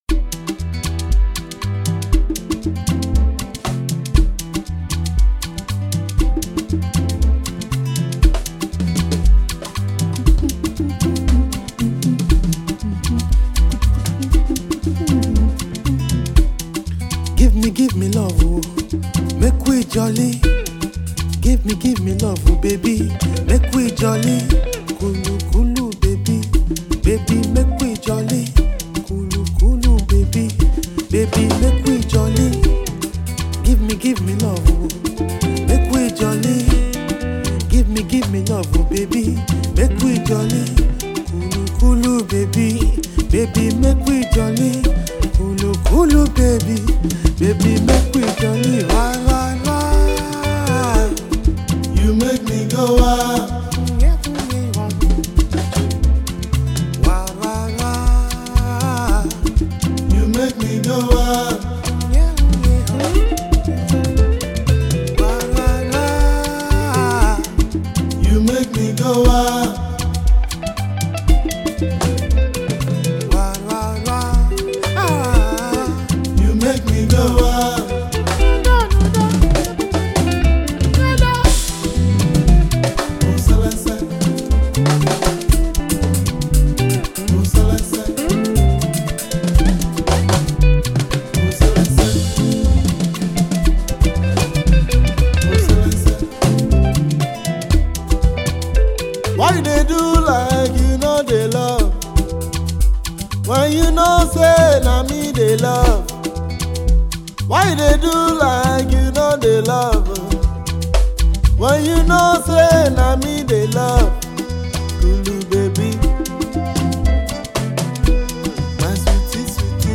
radio and club-friendly Jam